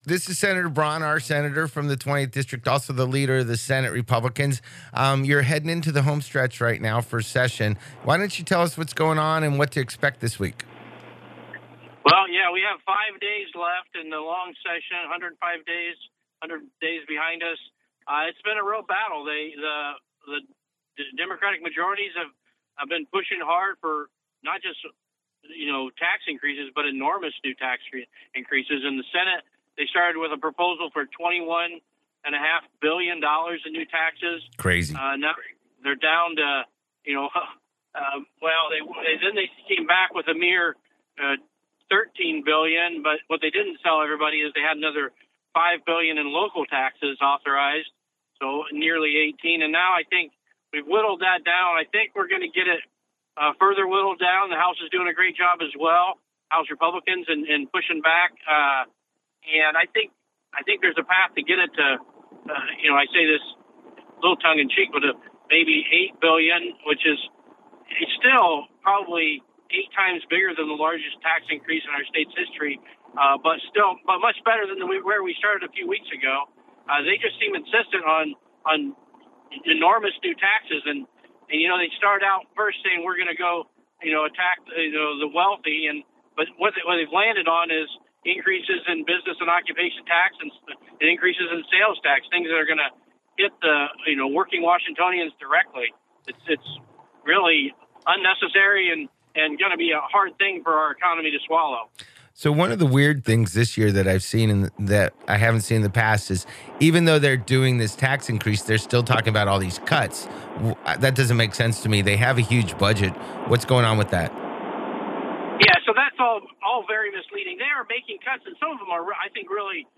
AUDIO: Senator John Braun Discusses Tax Battles and Legislative Challenges on KELA Radio - Senate Republican Caucus